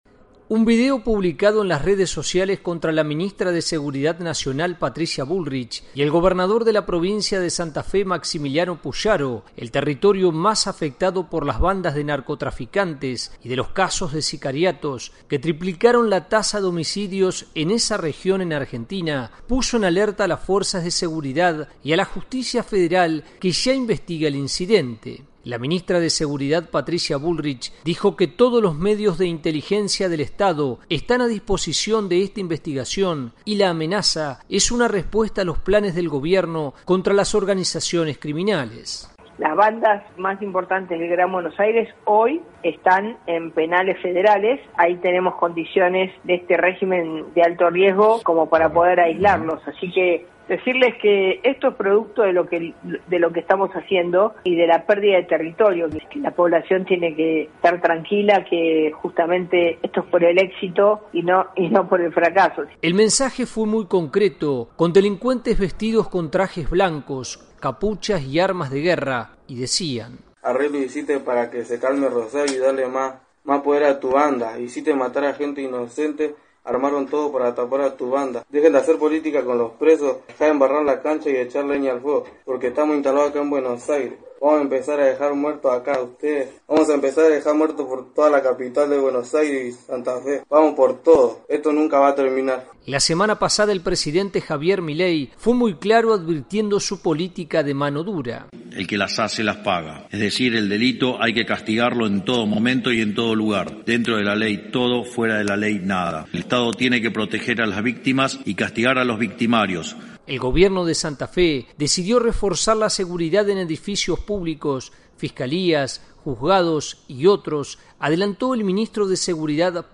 La justicia federal de Argentina investiga una amenaza de un grupo criminal contra el Estado que intenta modificar las políticas de “mano dura” y controles en las cárceles contra las bandas criminales más peligrosas. . Desde Buenos Aires informa el corresponsal